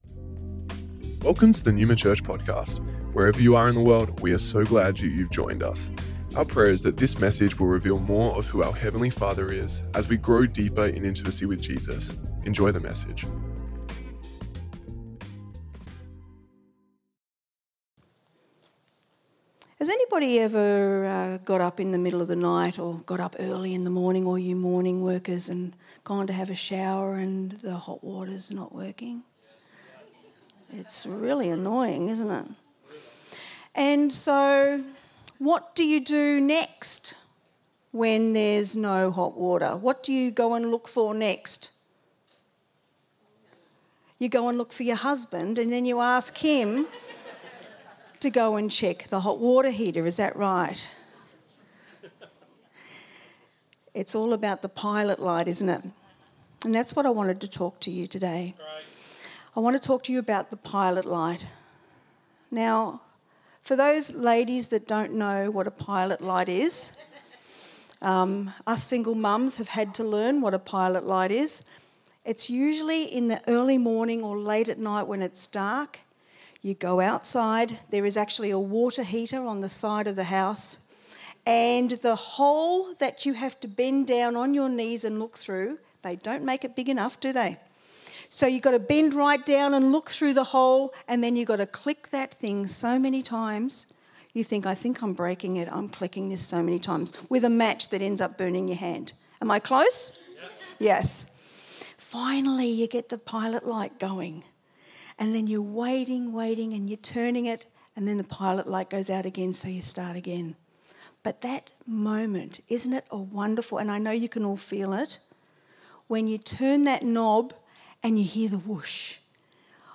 Originally recorded at Neuma Melbourne West August 4th 2024